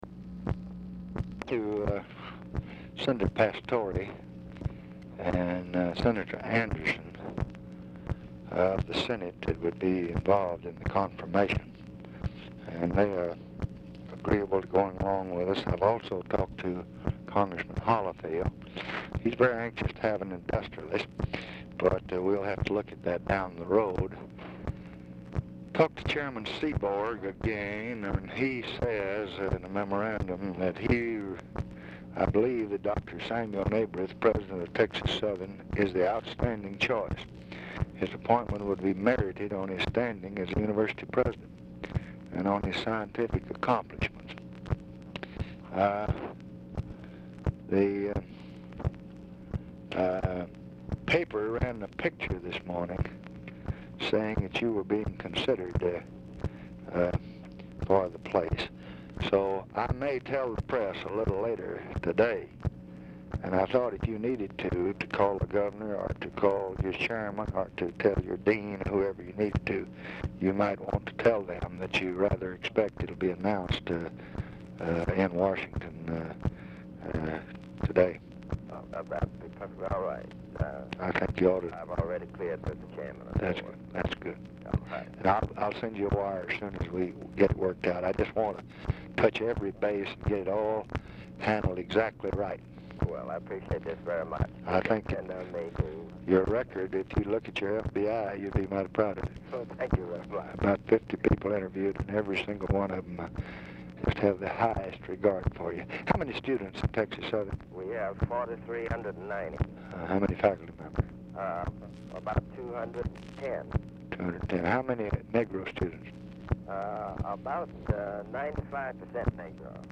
Telephone conversation # 10246, sound recording, LBJ and SAMUEL NABRIT, 6/18/1966, 10:55AM | Discover LBJ
RECORDING STARTS AFTER CONVERSATION HAS BEGUN
Format Dictation belt
Location Of Speaker 1 Oval Office or unknown location